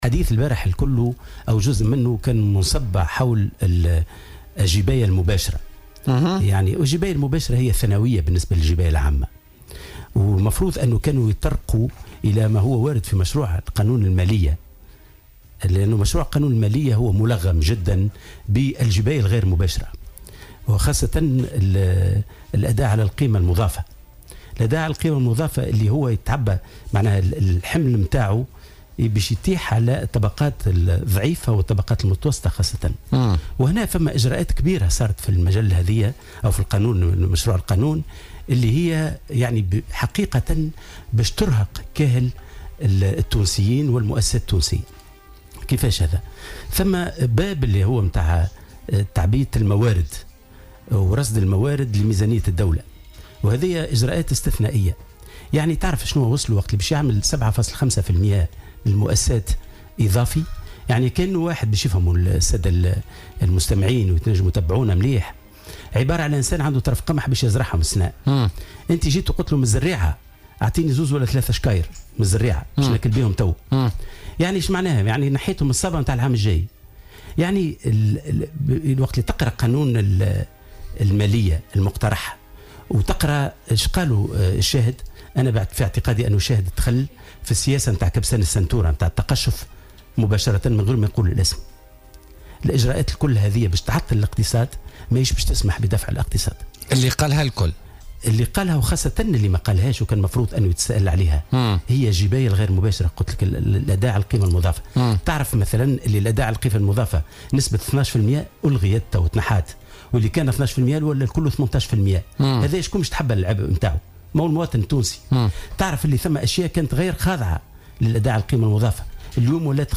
واعتبر ضيف "بوليتيكا" في "الجوهرة أف أم" أن مشروع المالية 2017 "ملغم" بالجباية غير المباشرة وستتكبد عبئها الطبقات المتوسطة والضعيفة وسترهق كاهل المواطنين.